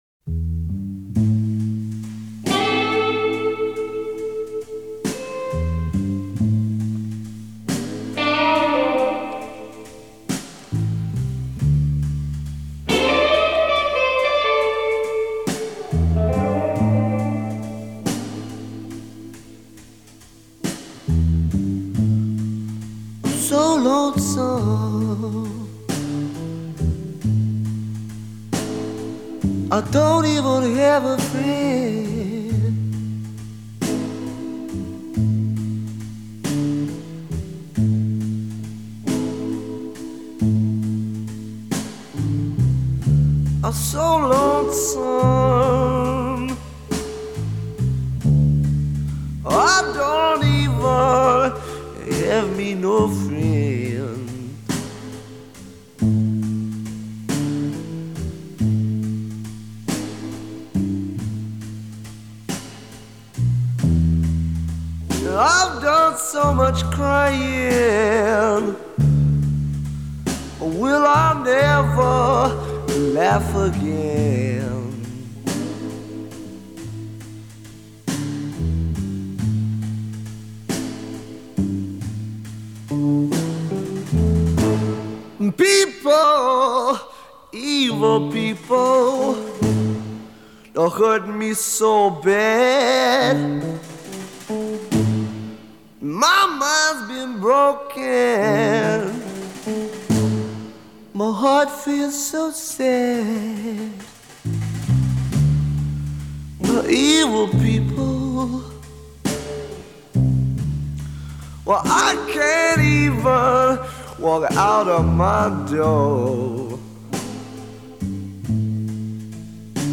Блюзы и блюзики